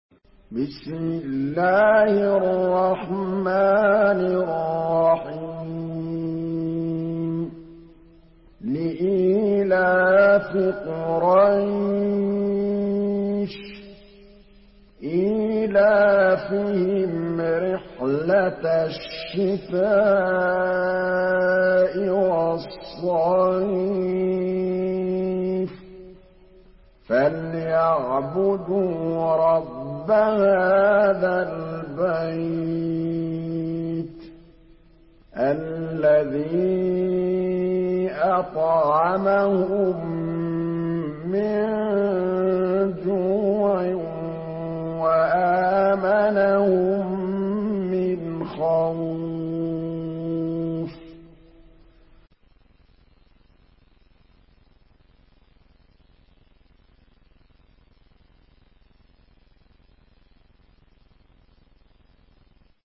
Surah قريش MP3 by محمد محمود الطبلاوي in حفص عن عاصم narration.